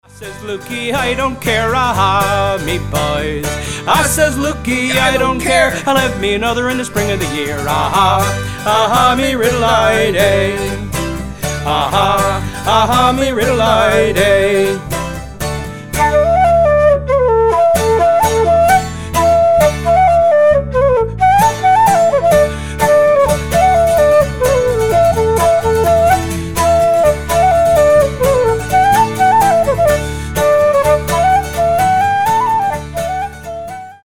- well known Newfoundland song and a reel